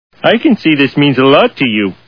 The Simpsons [Homer] Cartoon TV Show Sound Bites